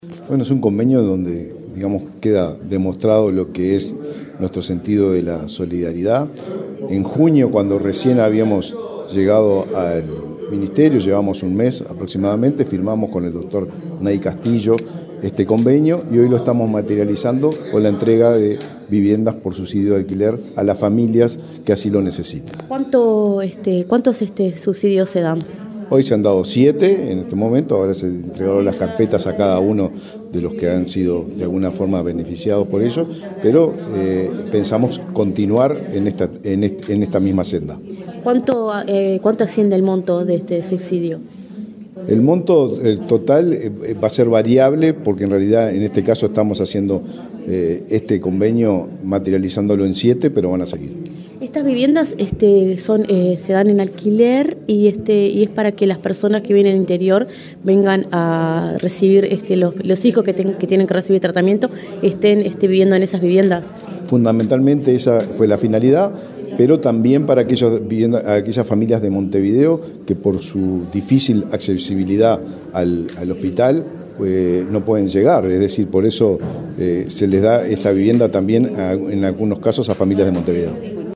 Declaraciones del ministro de Vivienda, Raúl Lozano
Declaraciones del ministro de Vivienda, Raúl Lozano 07/09/2023 Compartir Facebook X Copiar enlace WhatsApp LinkedIn Este jueves 7, en el Ministerio de Vivienda, el titular de la cartera, Raúl Lozano, entregó subsidios de alquiler a siete familias con integrantes que reciben atención en la fundación Pérez Scremini. Luego dialogó con Comunicación Presidencial.